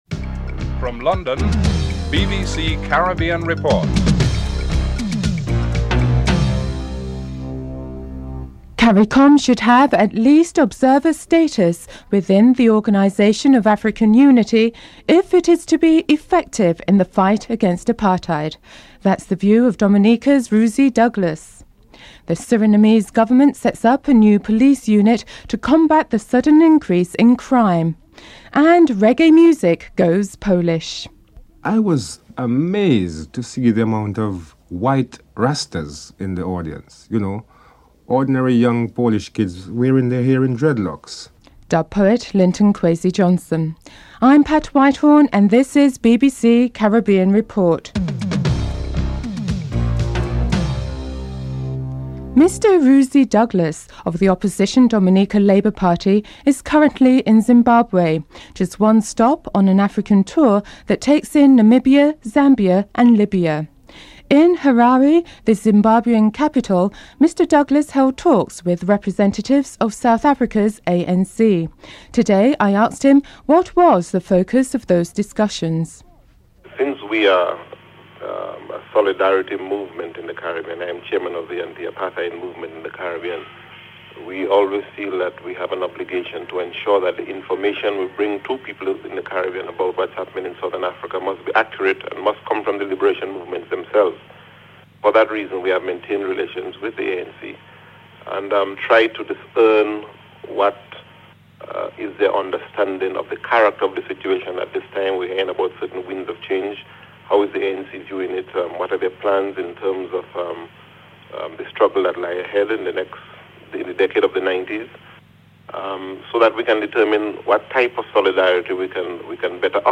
Includes musical excerpts from Linton Kwasi Johnson and Daab, a Polish reggae underground band.